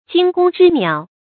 注音：ㄐㄧㄥ ㄍㄨㄙ ㄓㄧ ㄋㄧㄠˇ
讀音讀法：
驚弓之鳥的讀法